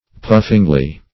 Search Result for " puffingly" : The Collaborative International Dictionary of English v.0.48: Puffingly \Puff"ing*ly\, adv.